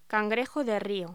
Locución: Cangrejo de río